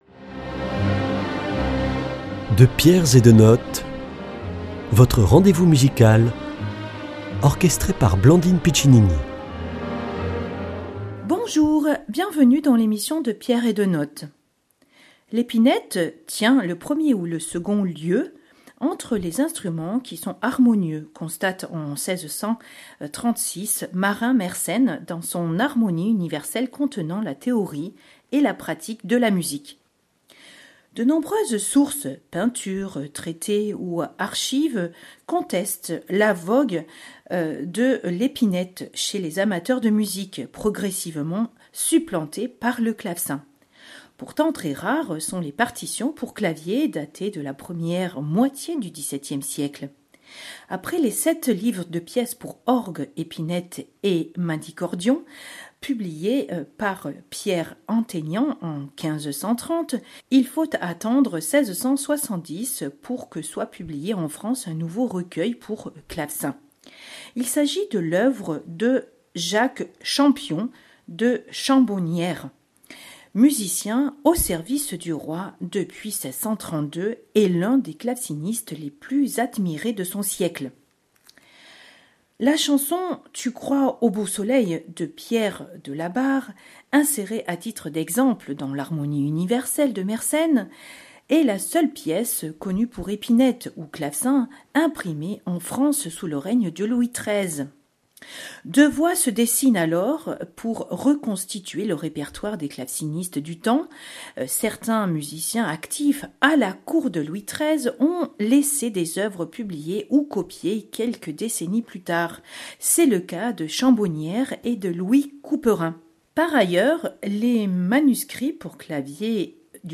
Au clavecin
les mouvements brusque, rondeau, pavane, Courante et Sarabande de Jacques de Champion de Chambonnières